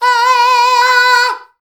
AAAAAAAAAH.wav